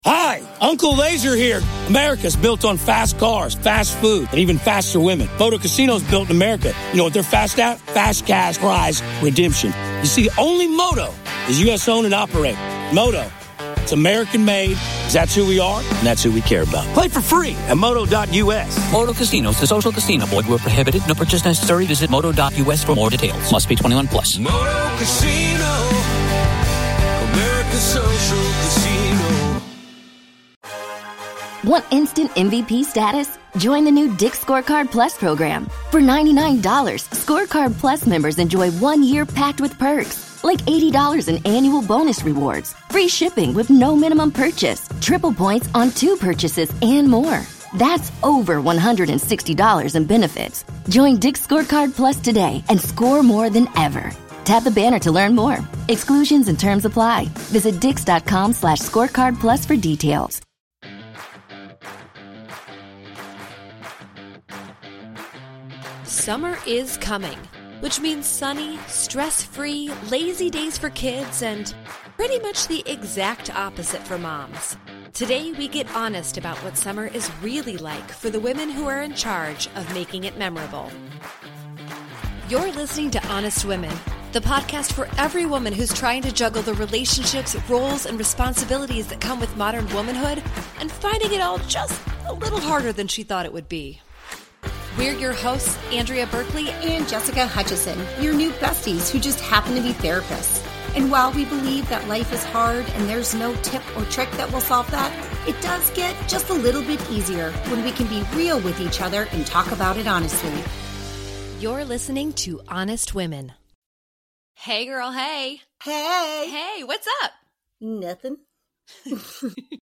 Disclaimer: Please note while this podcast features two therapists, and may feel very therapeutic, this is not therapy!